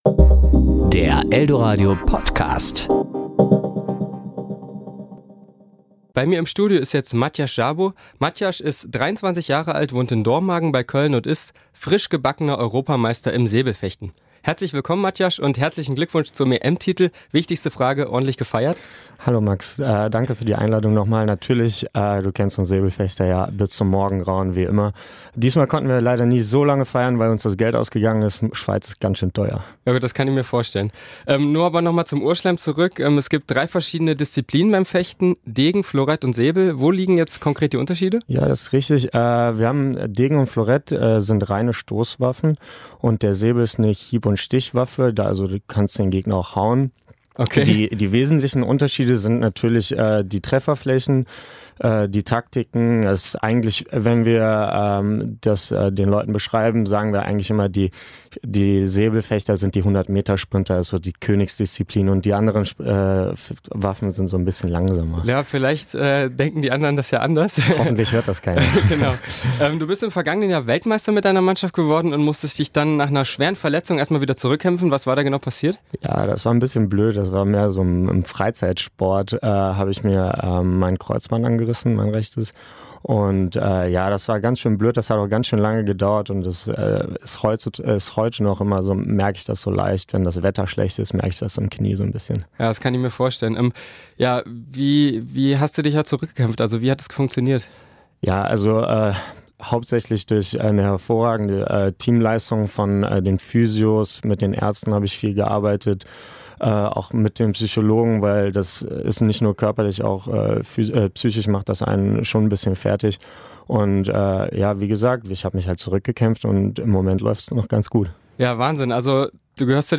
Serie: Beiträge  Ressort: Wort  Sendung: Toaster